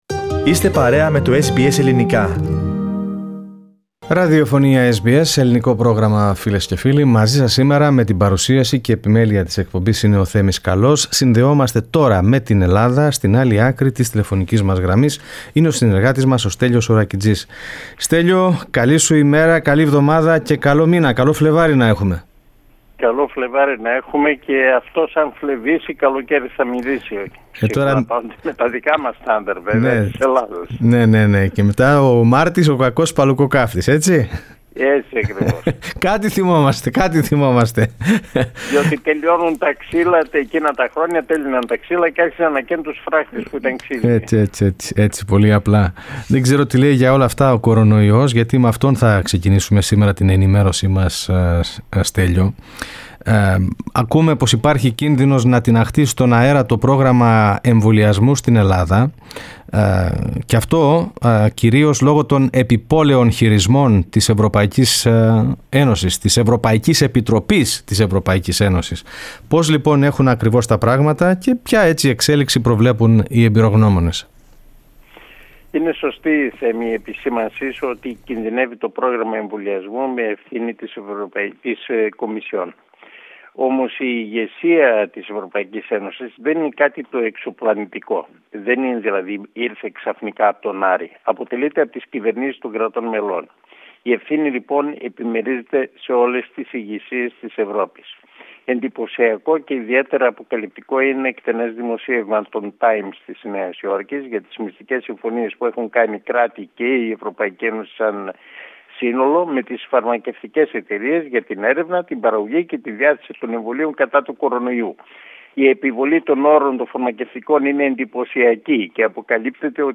Η εξέλιξη της πανδημίας, το πρόγραμμα των εμβολιασμών και η επόμενη μέρα μετά τις διερευνητικές επαφές Ελλάδας–Τουρκίας στην εβδομαδιαία ανταπόκριση από την Ελλάδα (01.02.2021)